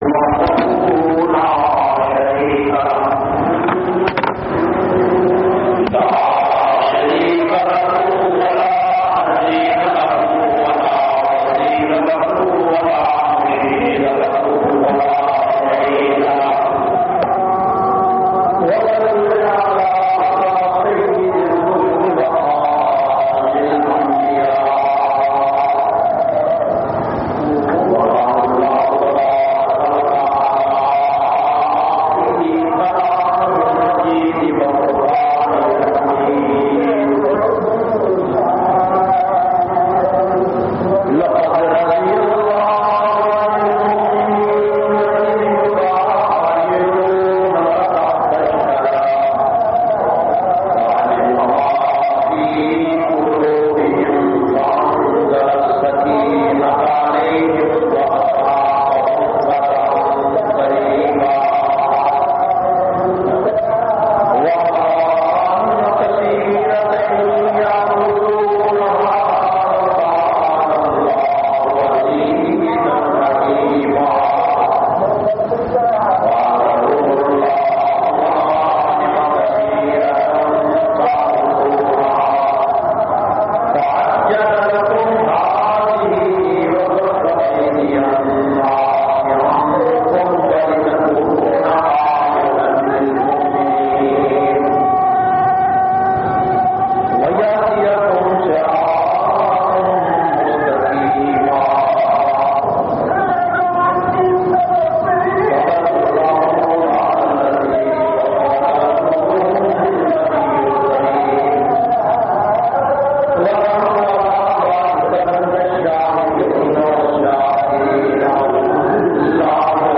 523- Sayyedena Usman Ghani Jumma Khutba Jamia Masjid Muhammadia Samandri Faisalabad.mp3